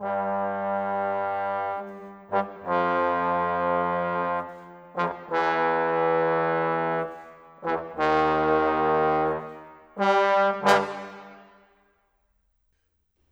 Rock-Pop 07 Trombones _ Tuba 01.wav